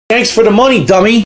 dsp-thanks-for-the-money-sound-effect_tFjjHj9.mp3